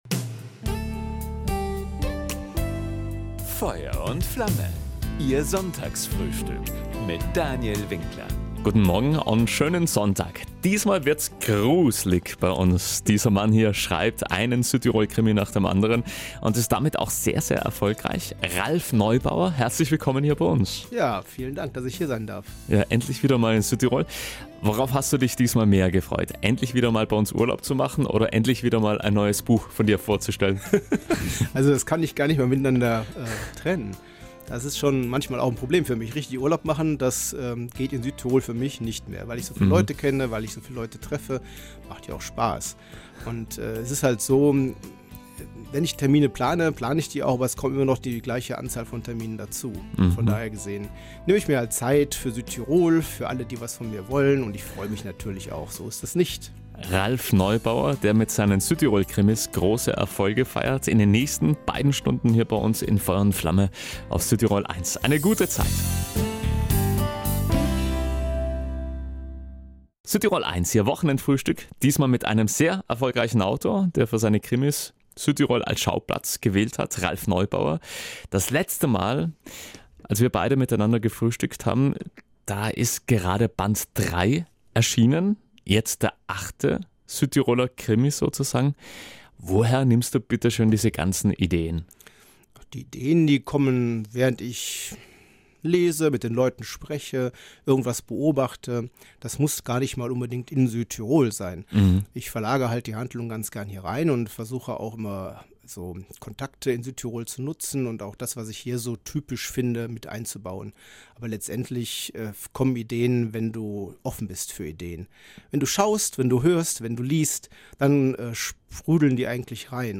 Ein Gespräch nicht nur darüber, warum ein Rheinländer Südtiroler Krimis schreibt.